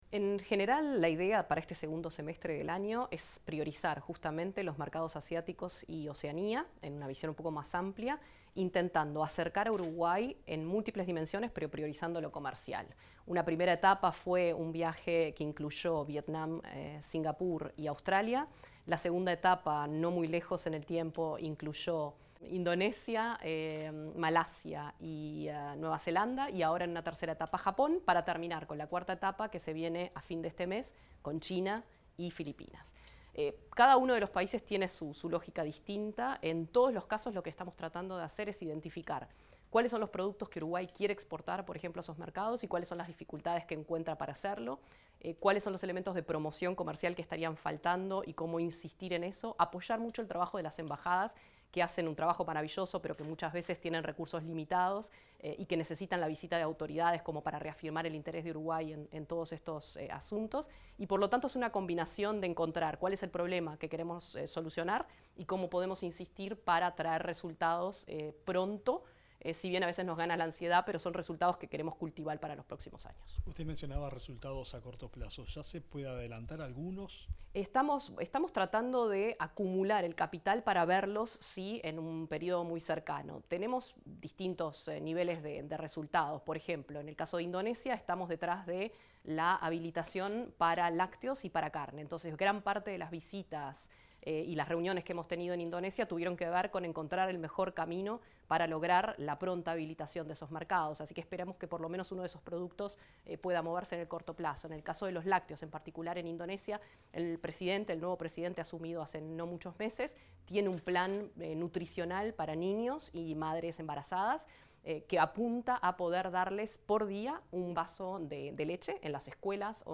Declaraciones de la ministra interina de Relaciones Exteriores, Valeria Csukasi
La canciller interina, Valeria Csukasi, realizó declaraciones tras regresar de una misión oficial a Indonesia, Nueva Zelanda, Malasia y Japón.